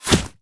pgs/Assets/Audio/Guns_Weapons/Bullets/bullet_impact_snow_05.wav
bullet_impact_snow_05.wav